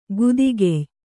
♪ gudigey